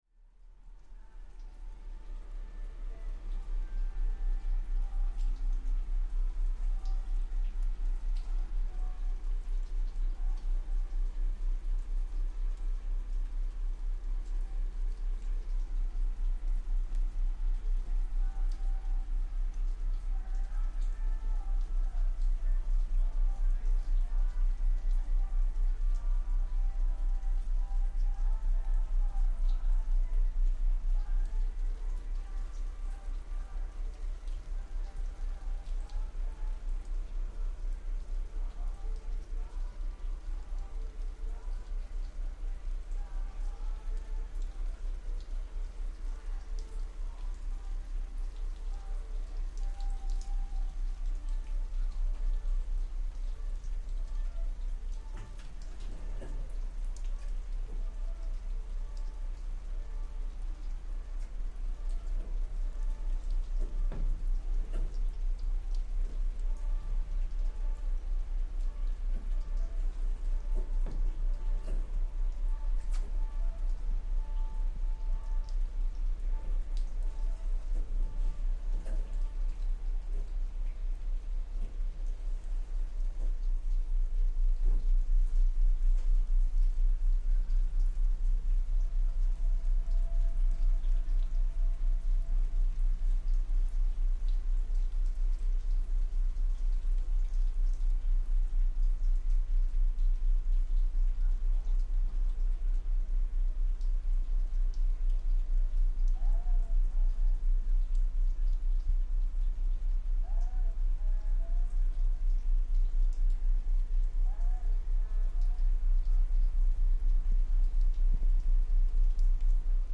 The intense throbbing of engines
Crossing the Baltic Sea from Tallinn to Helsinki aboard a large ferry, the deep pulsing of the ferry's engines mingles with light rain on the canopy overhead, and the faint piping of the music that can be heard much more loudly throughout the interior of the ferry.